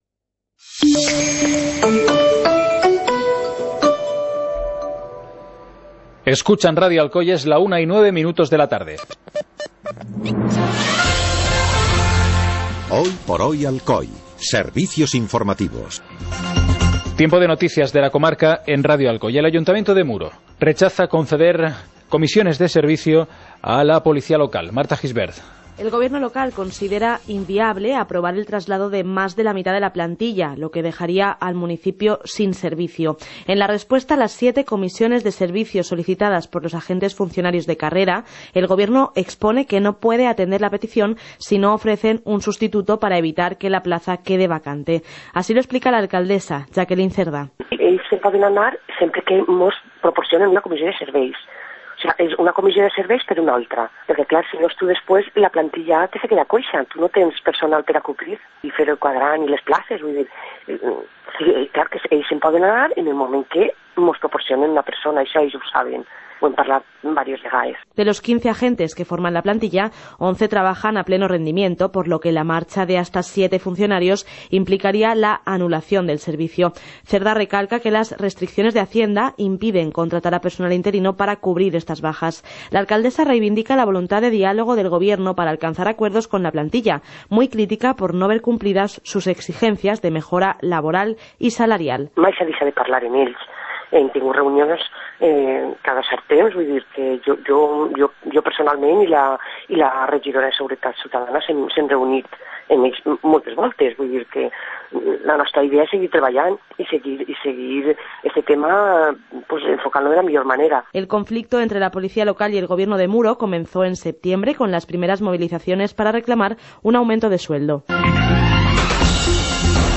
Informativo comarcal - martes, 20 de febrero de 2018